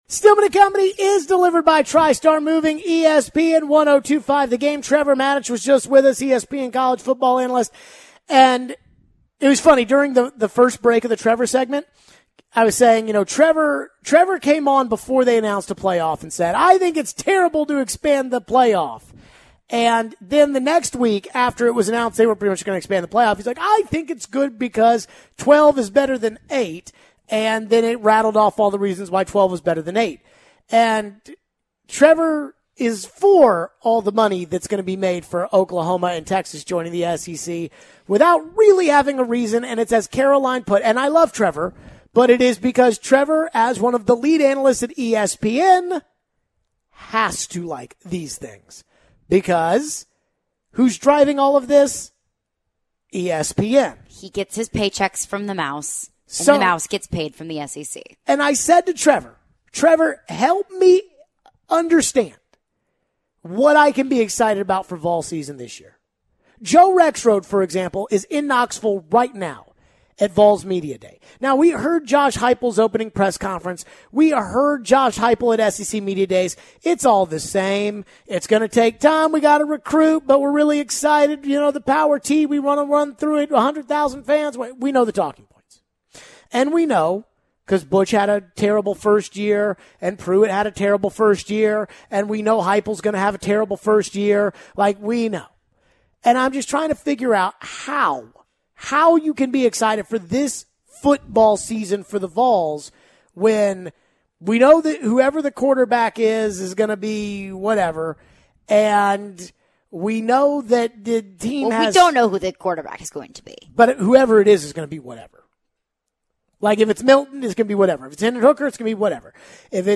Facebook Twitter Headliner Embed Embed Code See more options We carryover some of our Vols conversation from last hour. How much do the fans care this season and how excited are they about the season? We take your texts and calls on the Vols.